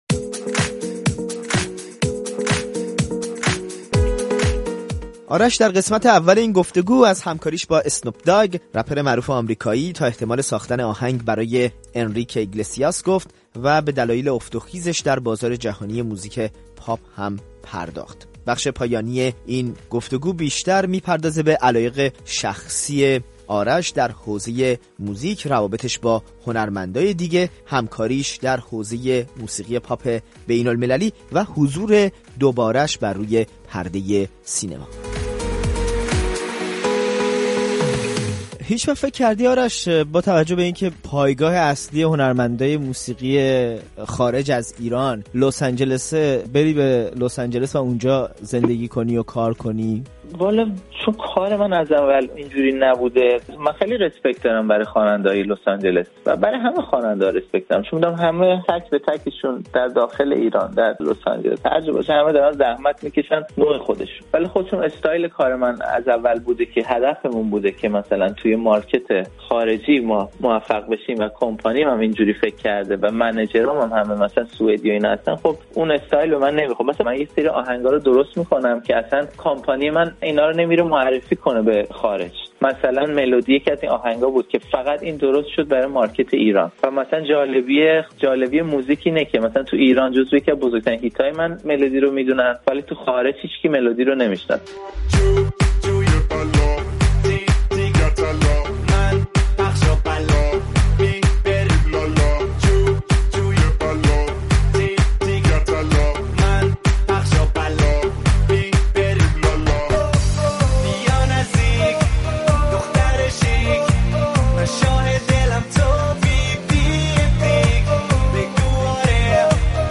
گفت‌وگو با آرش: خوانندگان داخل ایران «در باغی دیگرند»